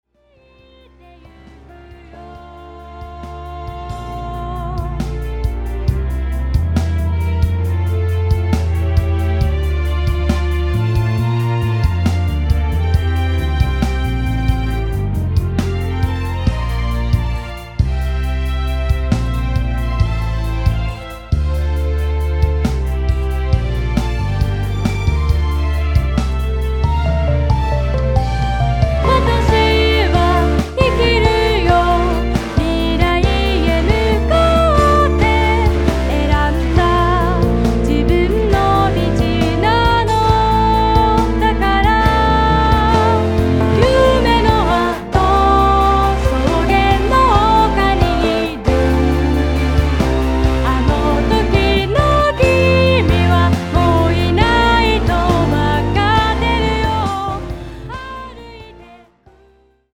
ポップスとクラシックをベースにしたオリジナル・ボーカルアルバム。